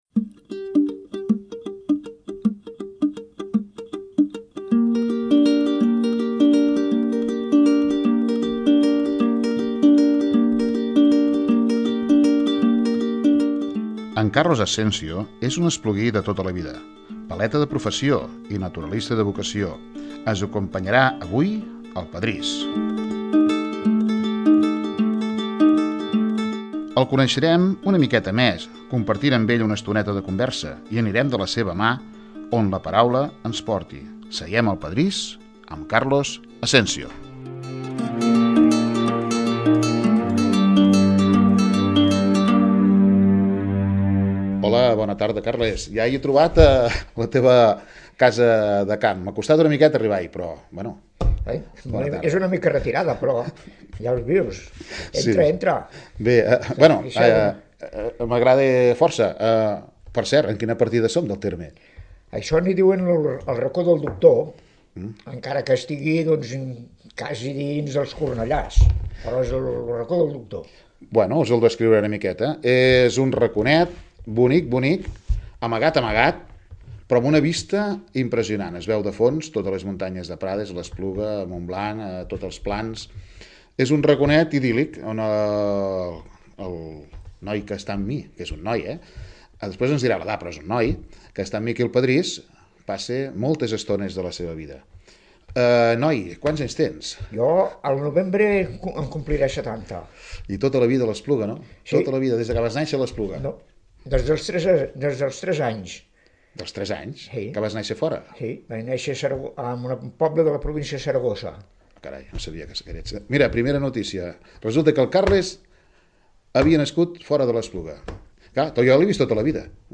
al seu terreny agrícola. El protagonista ens ha explicat alguns detalls de la seva vida i ens ha descobert les seves passions.